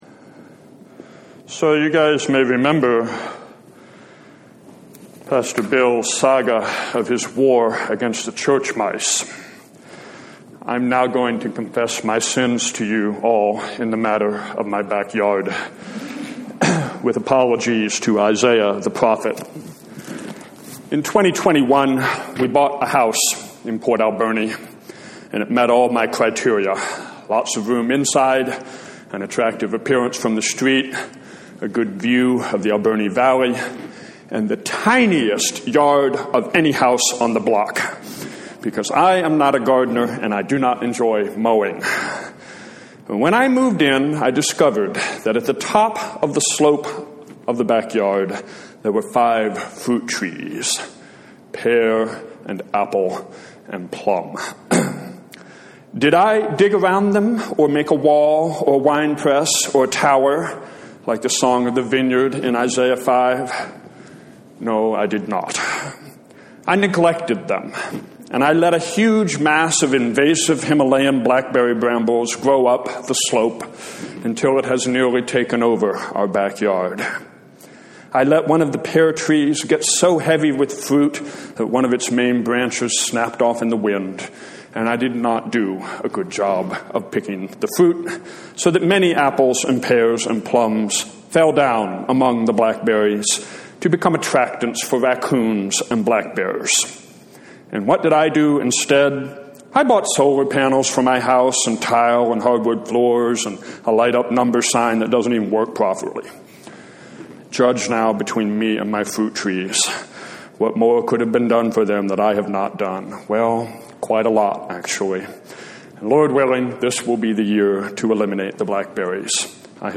A Sermon for Sexagesima